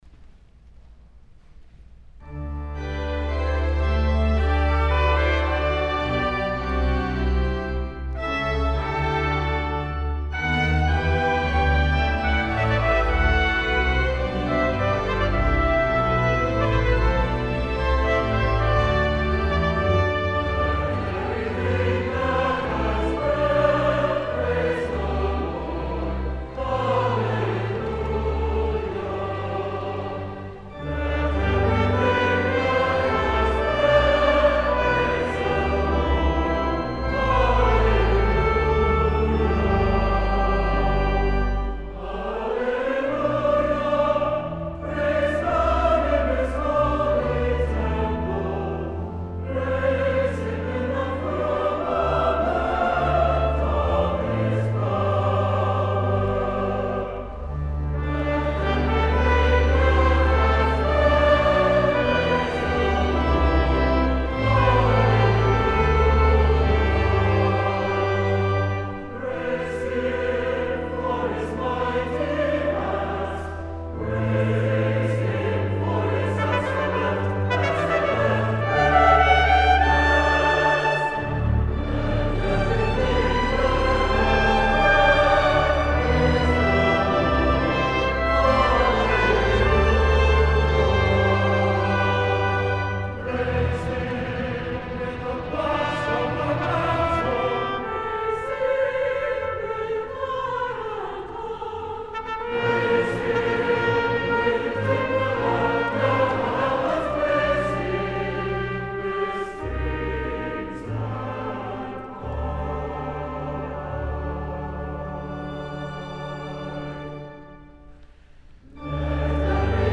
SATB, Trumpet, Organ, Assembly Refrain, opt. String Quartet